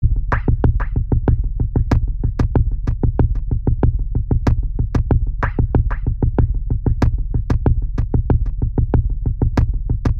music_layer_drums.mp3